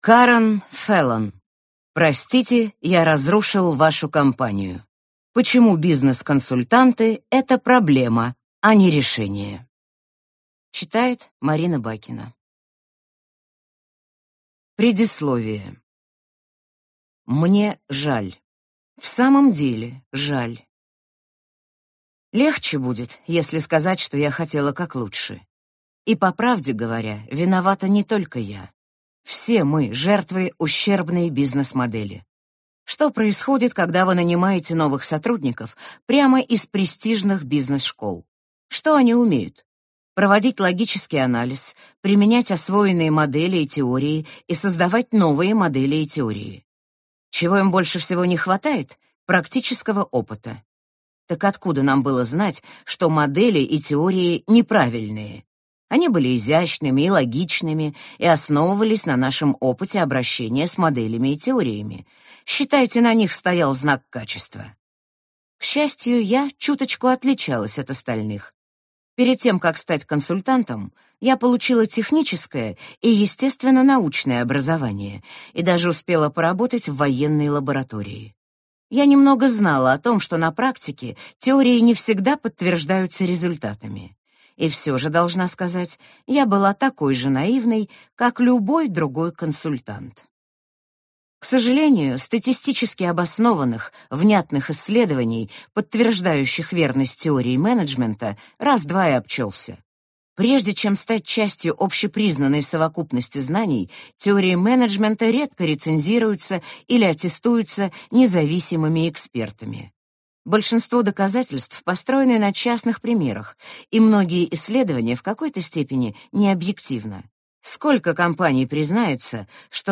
Аудиокнига Простите, я разрушил вашу компанию. Почему бизнес-консультанты – это проблема, а не решение | Библиотека аудиокниг